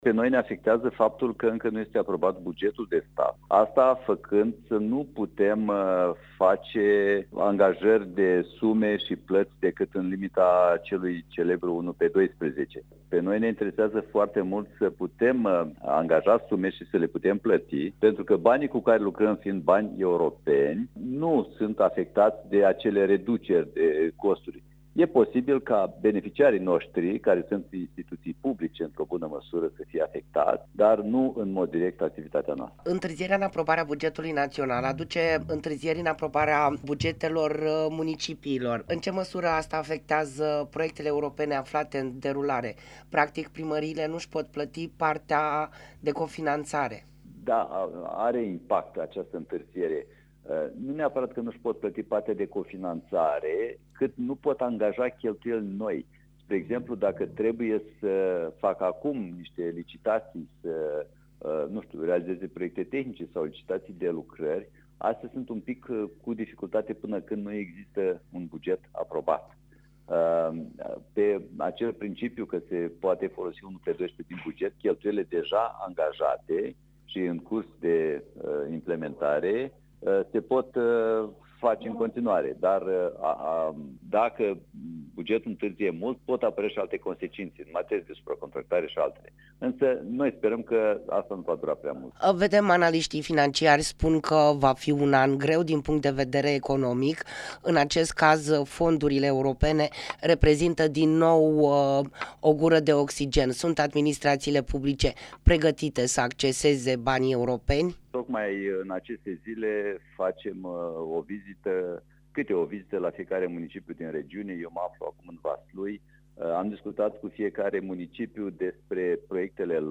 Interviu-Agentia-de-Dezvoltare-Nord-Est.mp3